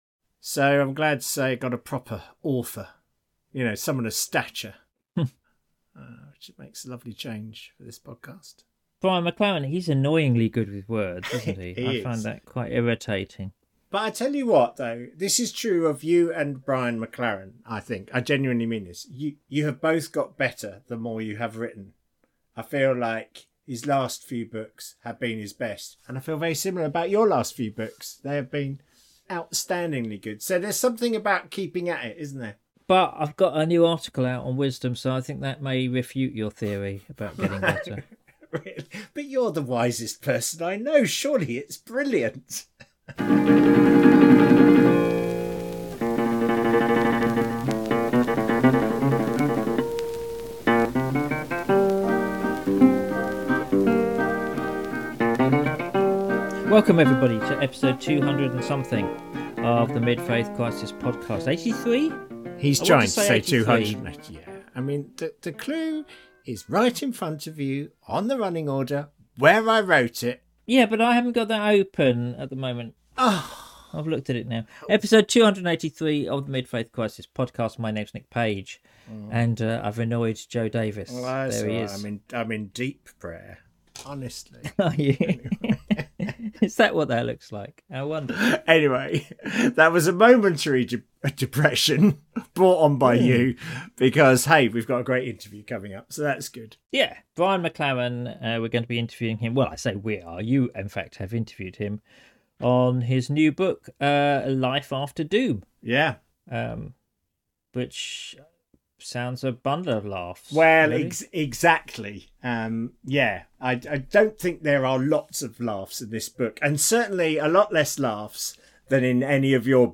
Episode 283: Life After Doom - an interview